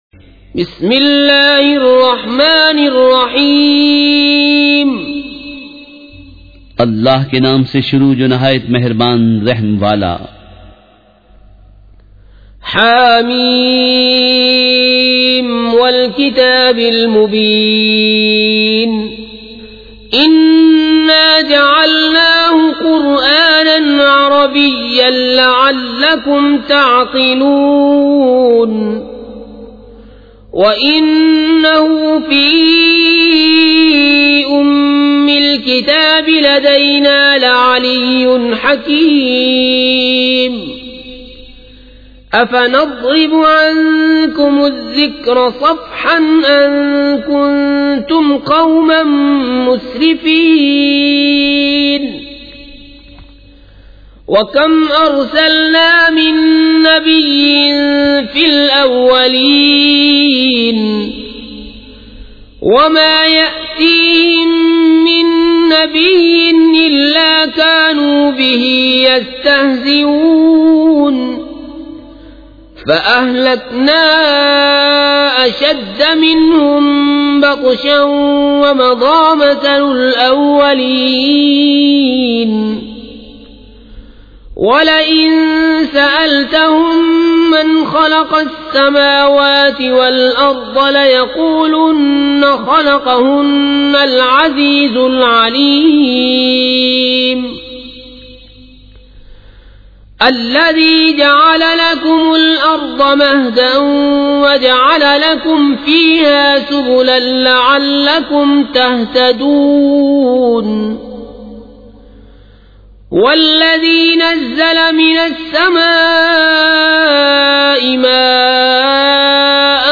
سورۃ الزخرف مع ترجمہ کنزالایمان ZiaeTaiba Audio میڈیا کی معلومات نام سورۃ الزخرف مع ترجمہ کنزالایمان موضوع تلاوت آواز دیگر زبان عربی کل نتائج 2058 قسم آڈیو ڈاؤن لوڈ MP 3 ڈاؤن لوڈ MP 4 متعلقہ تجویزوآراء